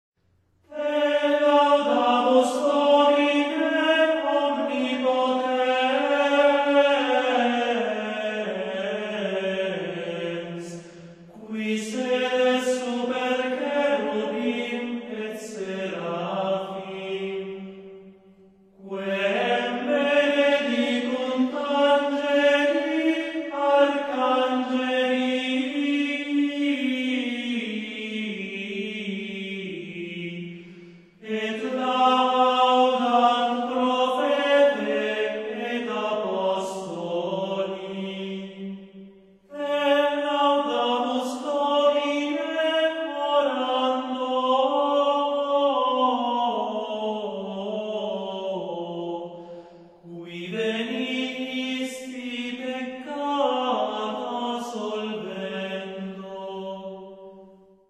Коллекция шестнадцати григорианских песнопений о Тайной Вечери в исполнении ансамбля "Cantori Gregoriani".
Запись сделана в храме Сан-Лоренцо ин Мондинари (Италия) в октябре 1995 г.